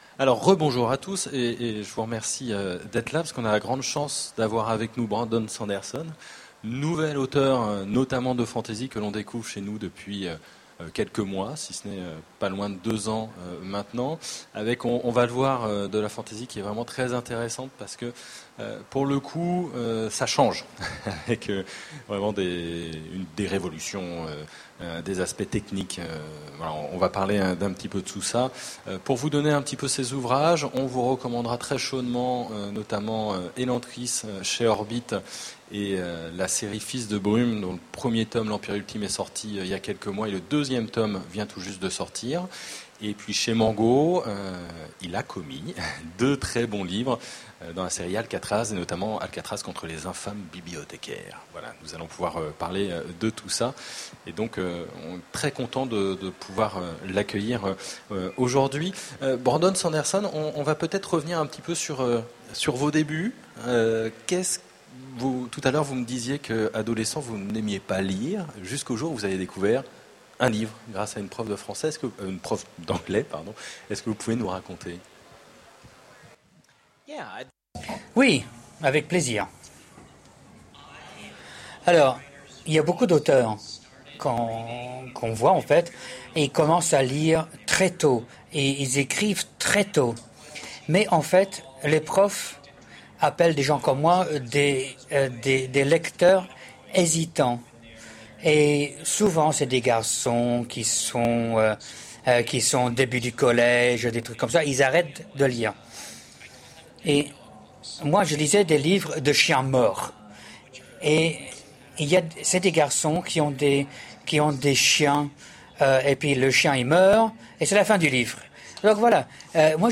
Voici l'enregistrement de la rencontre avec Brandon Sanderson aux Utopiales 2010.